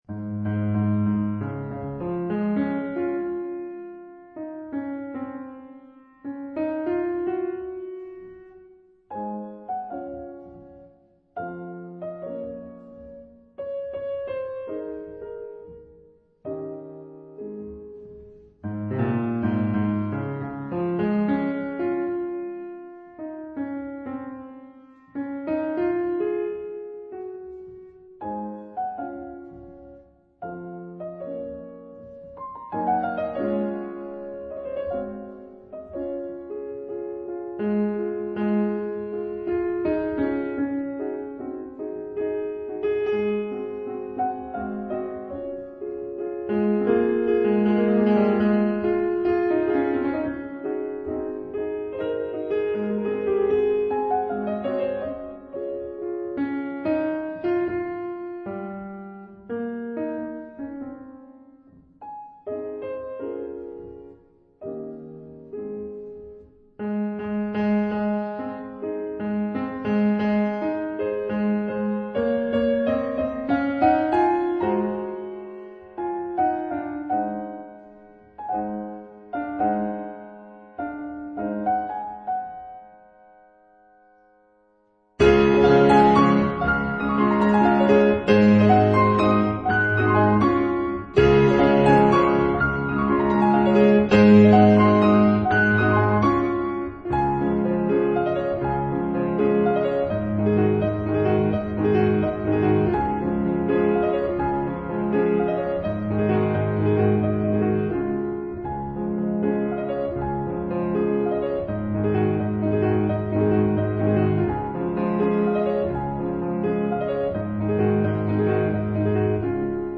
用力地說著一段舞會裡的邂逅。